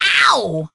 潘妮是达里尔海盗船上的一员，声音像个假小子，语音多为各种笑声。
Penny_hurt_vo_03.ogg